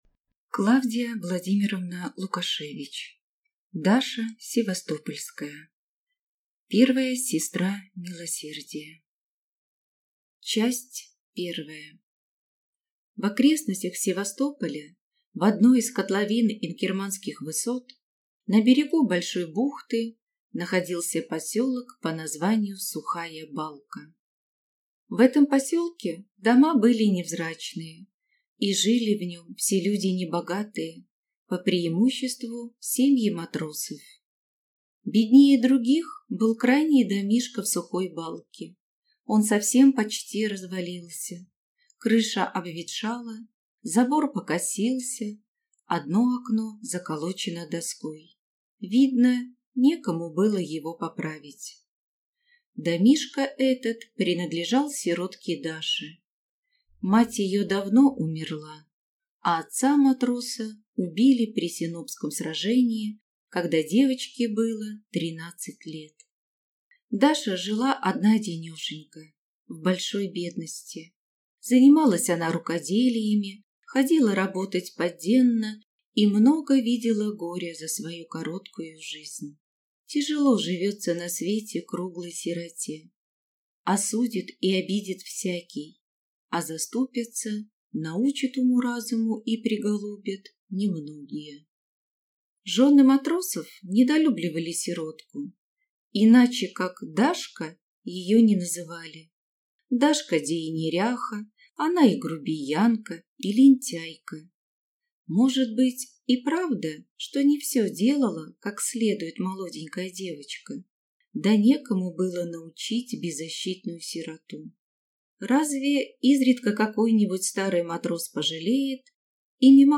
Аудиокнига Даша севастопольская | Библиотека аудиокниг
Прослушать и бесплатно скачать фрагмент аудиокниги